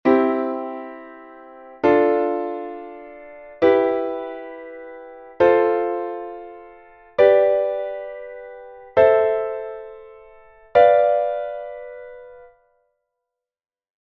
Escala de do formando acordes
escala_acordes.mp3